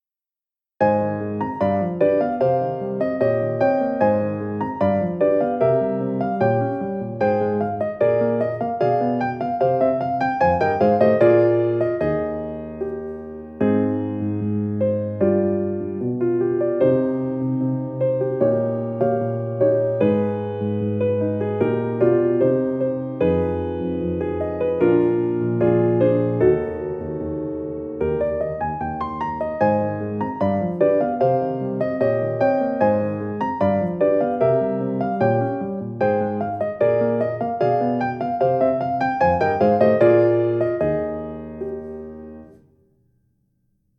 ボカロっぽいコード進行のオフボーカル